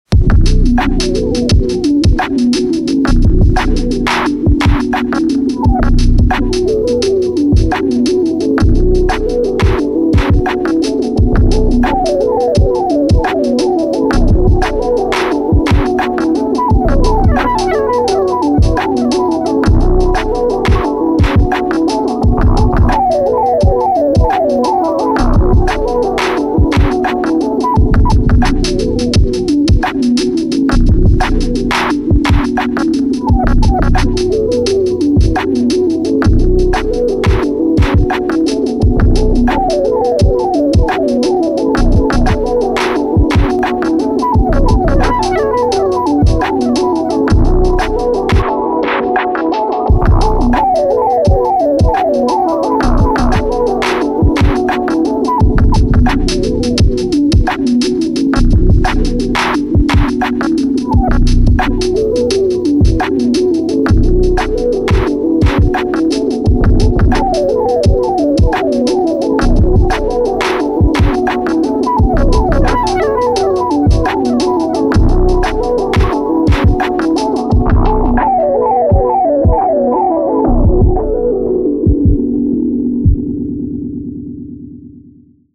Paired the SP with my Pioneer AS-1 which I’ve been massively neglecting. Levels all over the place playing around with skipback and resampling but anyways.
Organic with hints of chill lofi and old school hip hop.